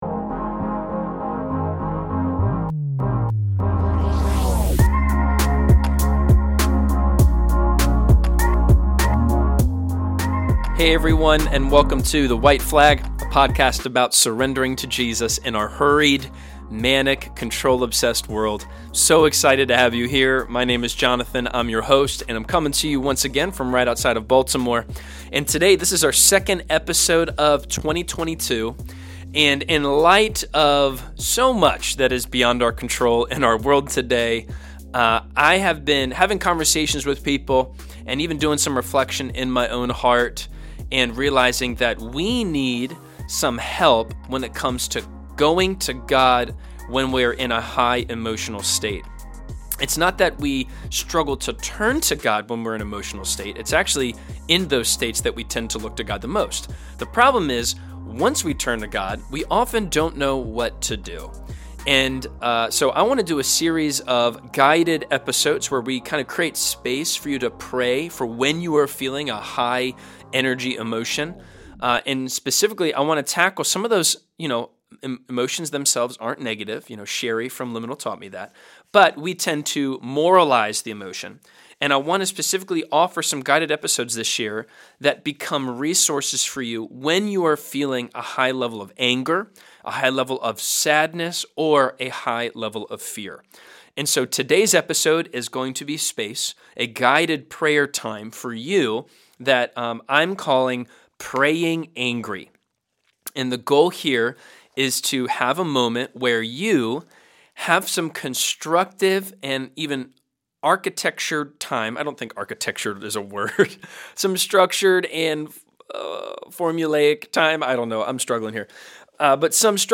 Too often, we confine or curate our emotions to what we THINK God wants instead of bringing our full selves to Him. In this time of guided prayer, I try to create space for you to bring your anger to God and explore what the Holy Spirit wants to do in and through that emotion.